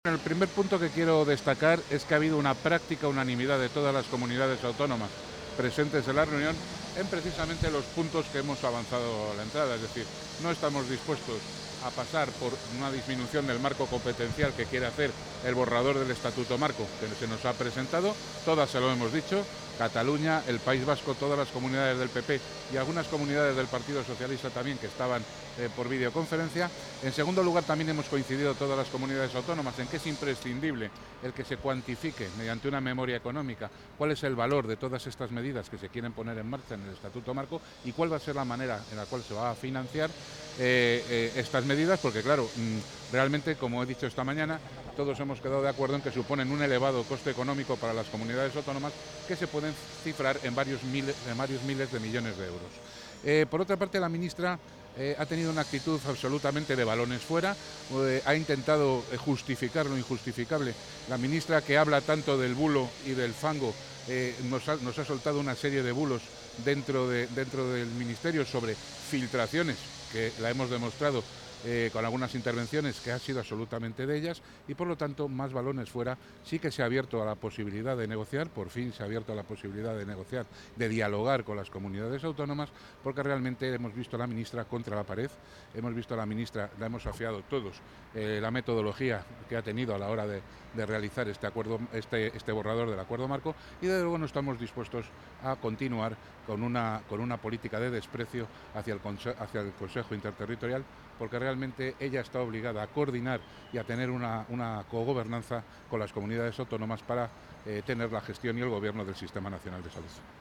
Declaraciones del consejero de Sanidad posteriores a la celebración de la Comisión de Recursos Humanos del Sistema Nacional de Salud